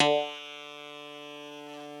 genesis_bass_038.wav